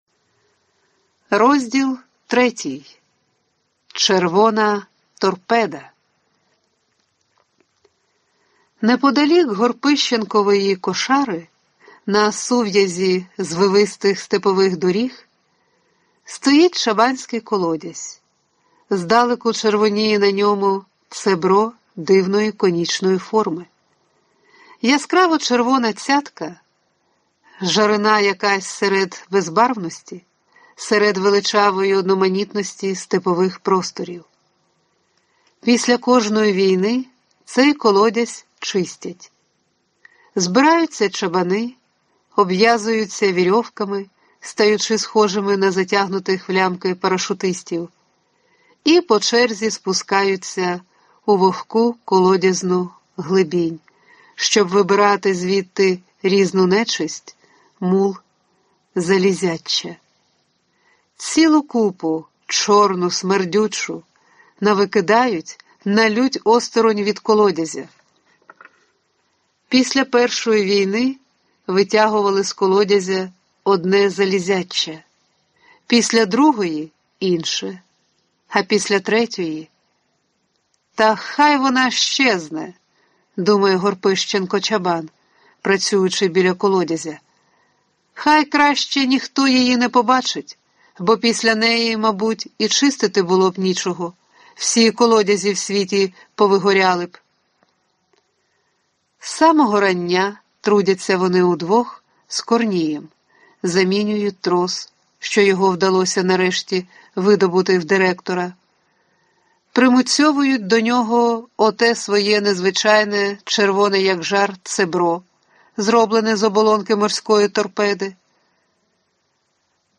Аудиокнига Червона торпеда. Новела | Библиотека аудиокниг